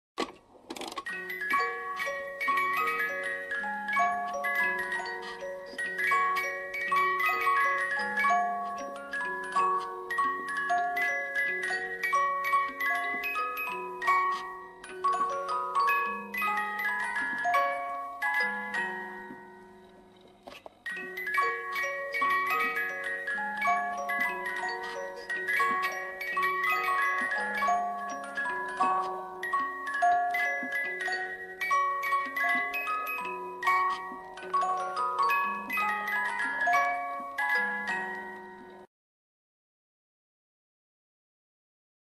"헤이, 슬라브인들이여"를 음악 상자로 연주한 모습